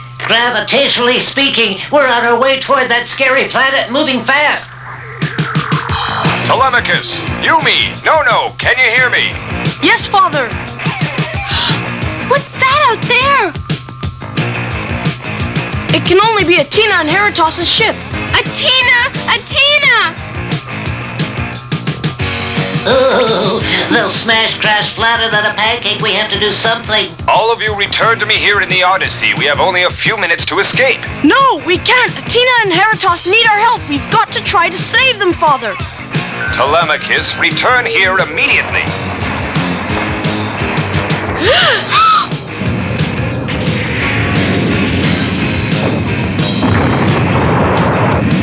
Looks like they might all be in a spot of bother. Listen out for the “Ahhh’s” and “Ohhh’s”!